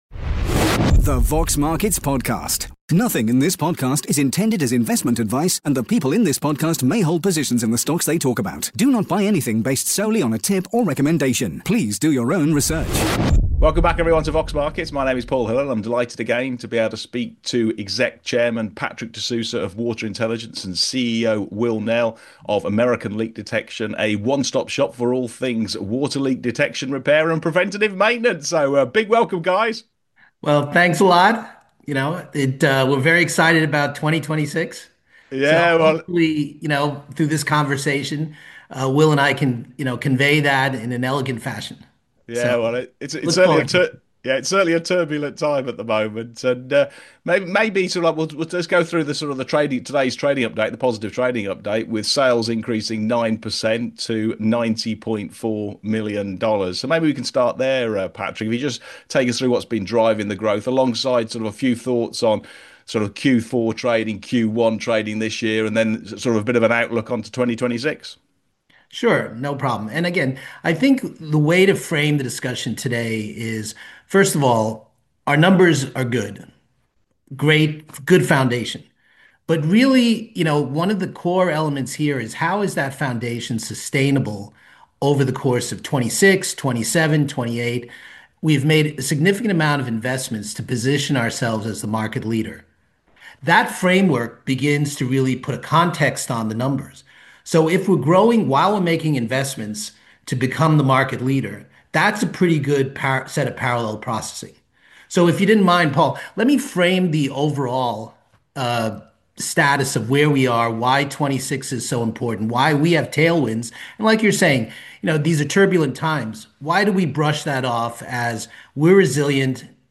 Q&A with Water Intelligence